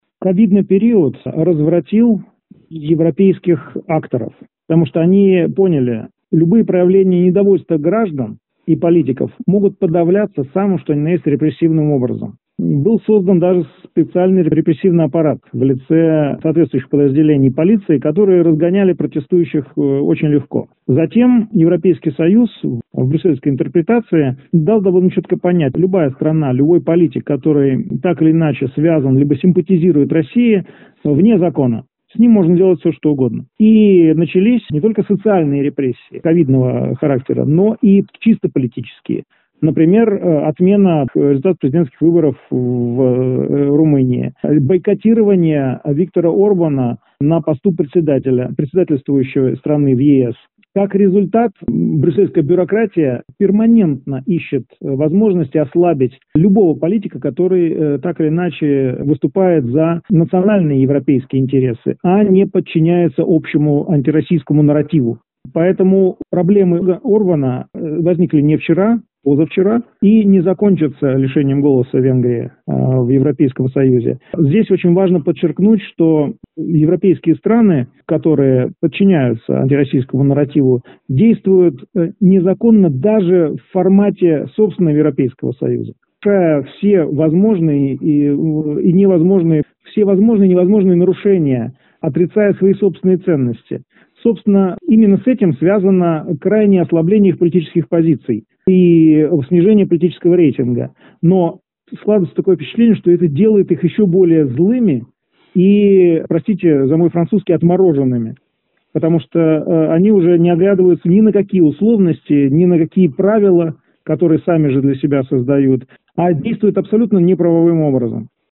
ГЛАВНАЯ > Актуальное интервью